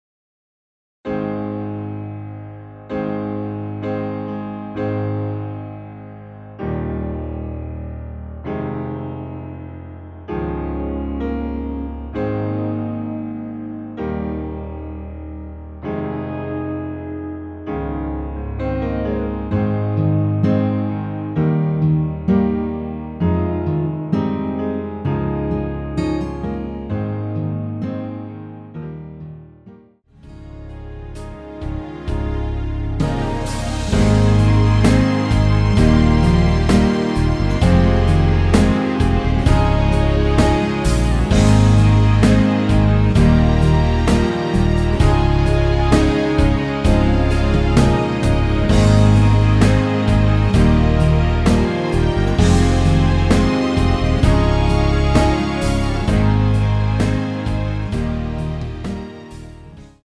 (-2) 내린 MR입니다.
노래가 바로 시작 하는곡이라 전주 1마디 만들어 놓았습니다.(미리듣기 참조)
Ab
앞부분30초, 뒷부분30초씩 편집해서 올려 드리고 있습니다.
중간에 음이 끈어지고 다시 나오는 이유는